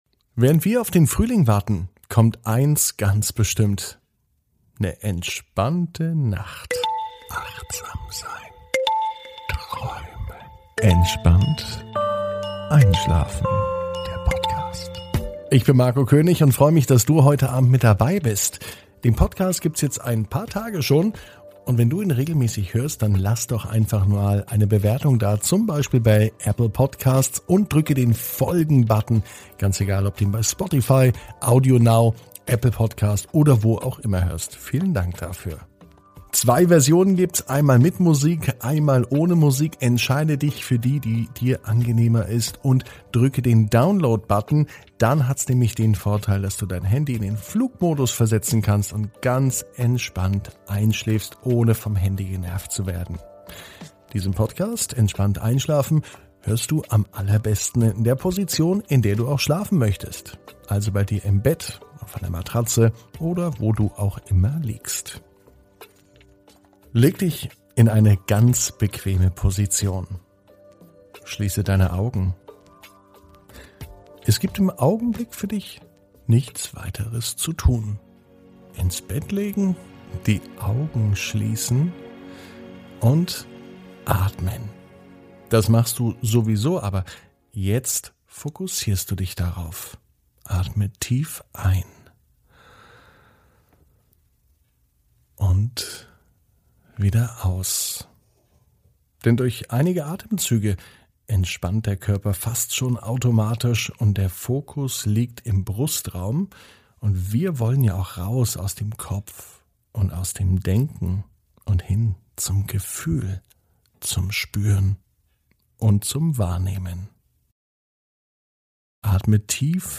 (ohne Musik) Entspannt einschlafen am Mittwoch, 05.05.21 ~ Entspannt einschlafen - Meditation & Achtsamkeit für die Nacht Podcast